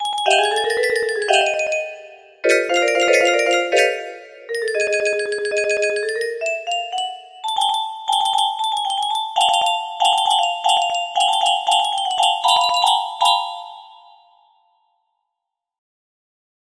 MEMES music box melody